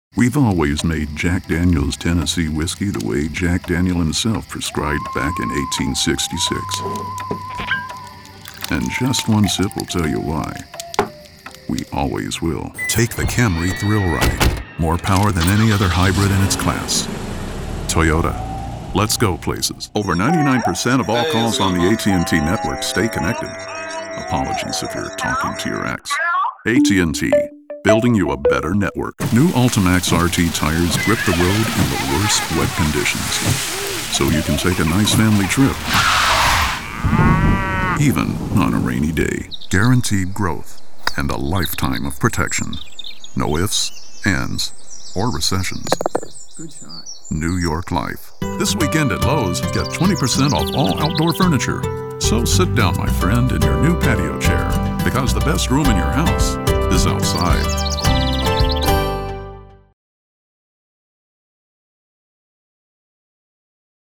Southern, Western
Middle Aged